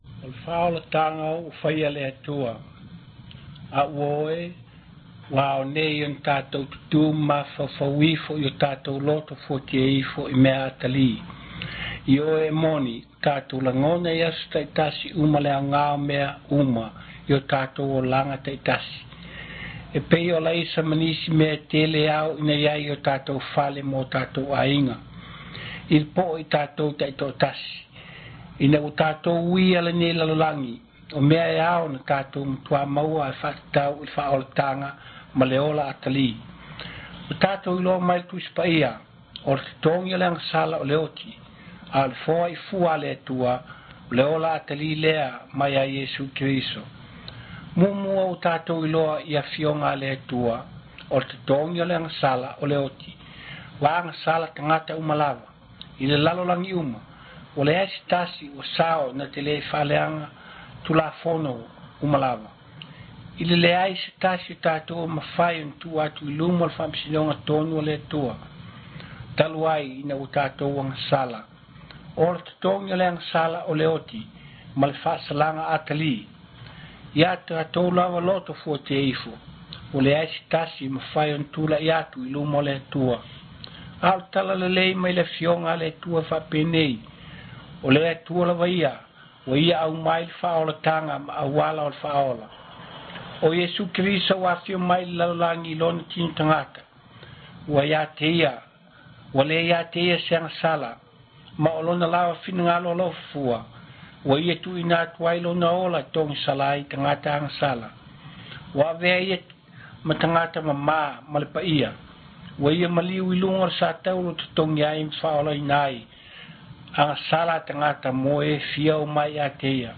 NT Drama